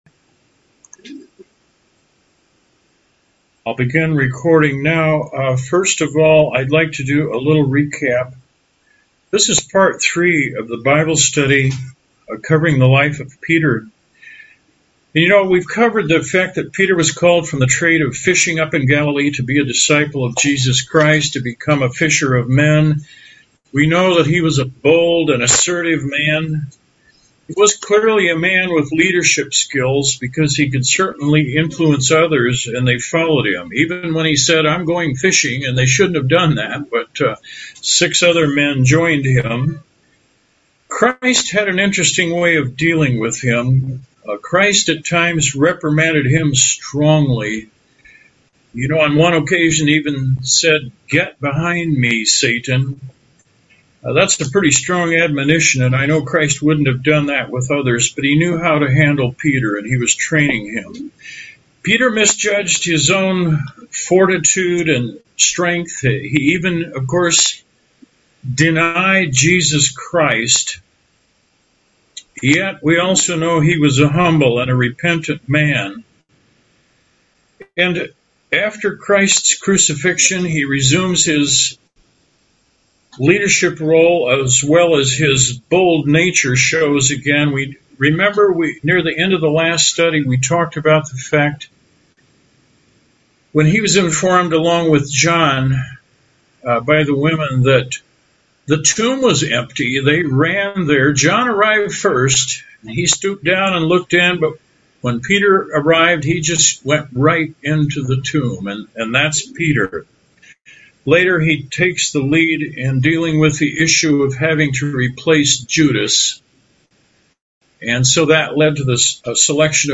This is the final segment of the bible studies on the apostle Peter. The study covers a greatly changed man after he received the Holy Spirit and became deeply converted servant of God. Pardon the abrupt end of the video.